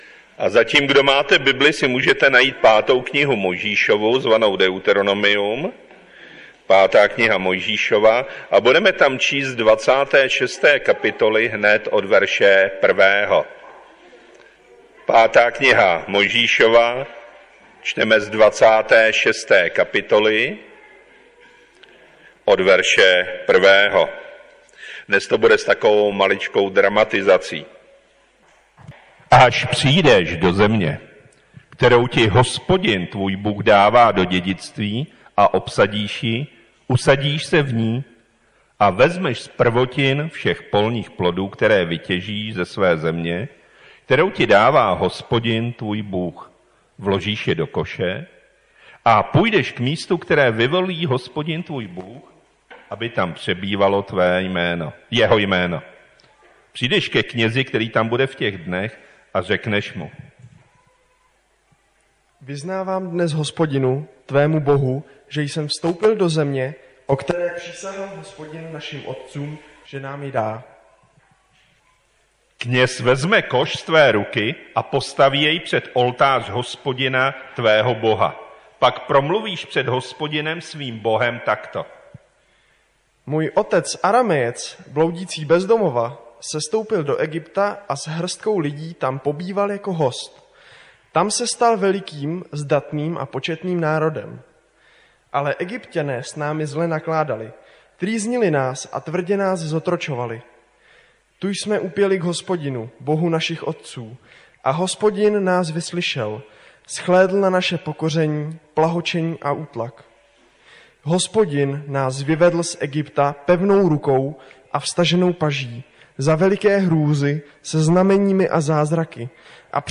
Mojžíšova 26:1–15 4 října 2020 Řečník: ---více řečníků--- Kategorie: Nedělní bohoslužby Husinec přehrát / pozastavit Váš prohlížeč nepodporuje přehrávání audio souborů. stáhnout mp3